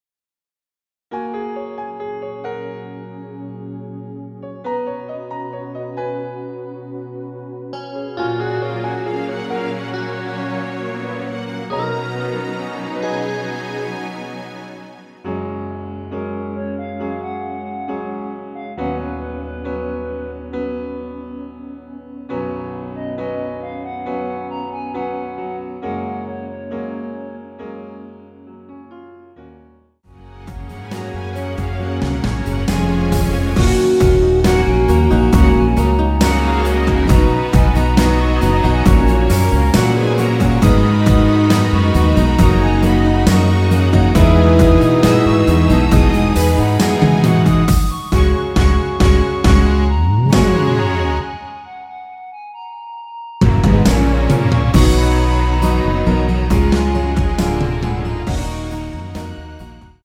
원키 멜로디 포함된 MR 입니다.
F#
앞부분30초, 뒷부분30초씩 편집해서 올려 드리고 있습니다.
중간에 음이 끈어지고 다시 나오는 이유는